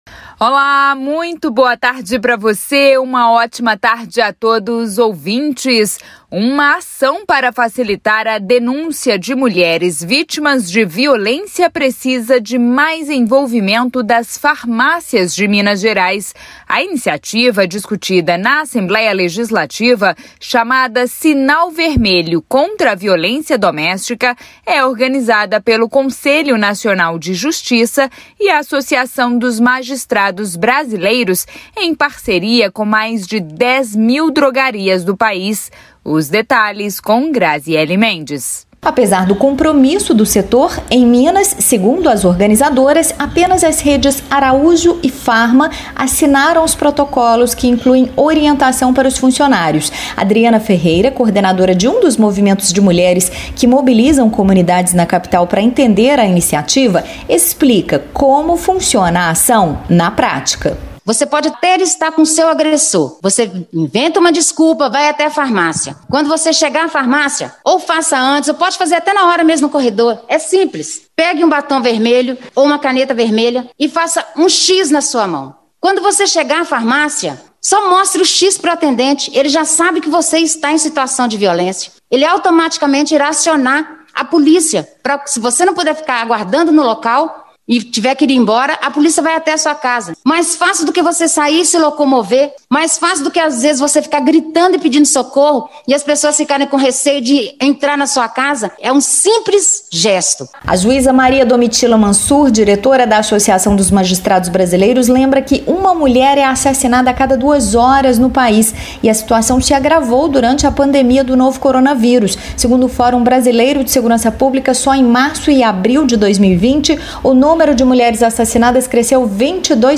A demanda foi feita em audiência pública da Comissão de Defesa dos Direitos da Mulher da Assembleia Legislativa de Minas Gerais (ALMG).
Sonora-ALMG-02-de-Outubro.mp3